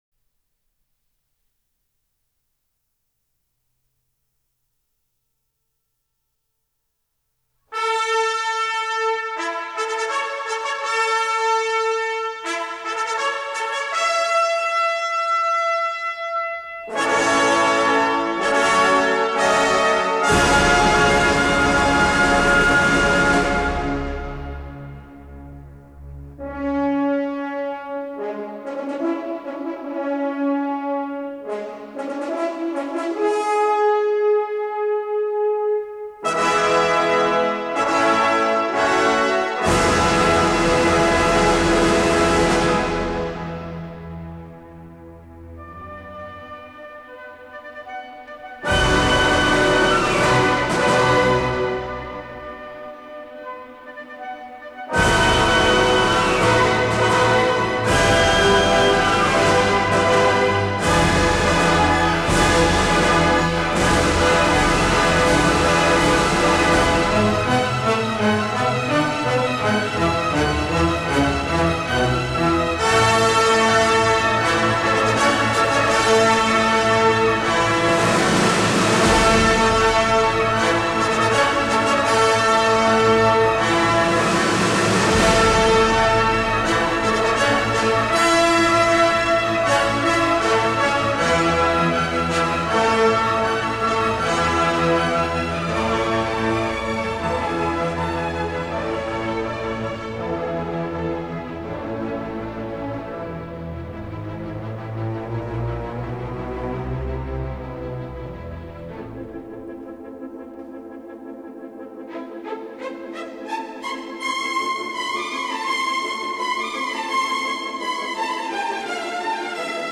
Recorded at the Sofiensaal in Vienna.